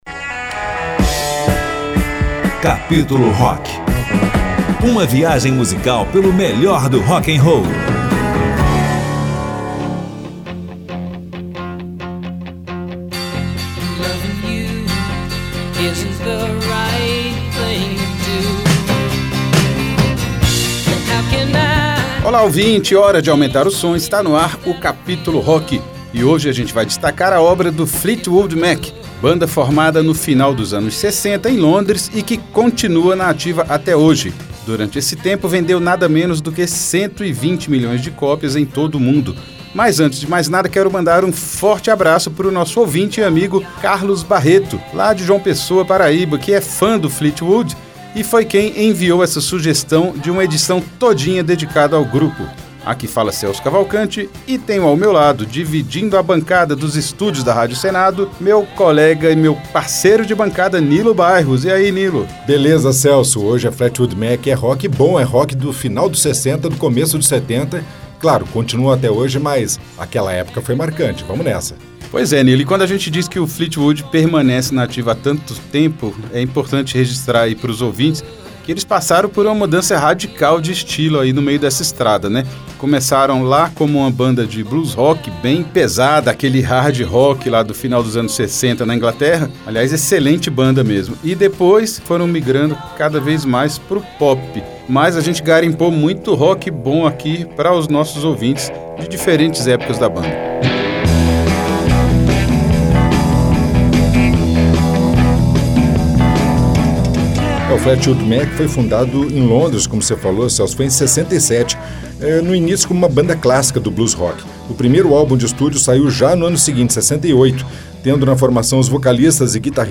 a parte mais roqueira de sua obra em todas as épocas